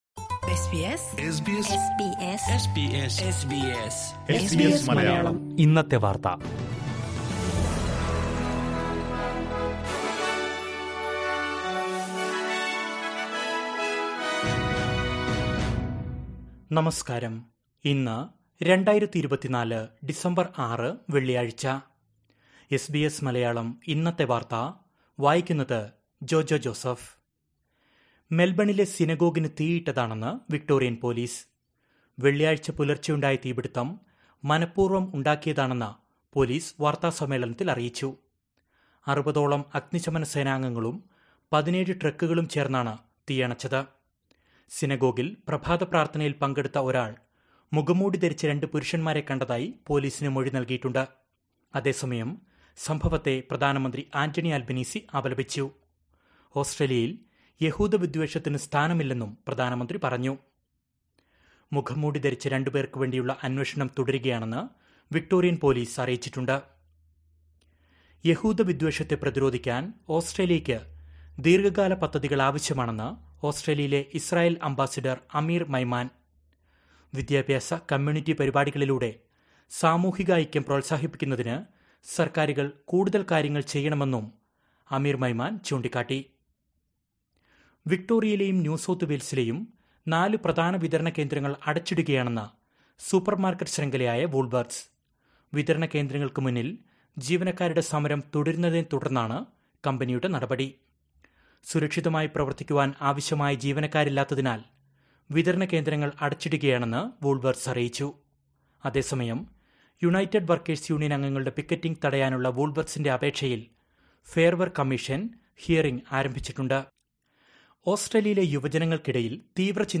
2024 ഡിസംബര്‍ ആറിലെ ഓസ്‌ട്രേലിയയിലെ ഏറ്റവും പ്രധാന വാര്‍ത്തകള്‍ കേള്‍ക്കാം...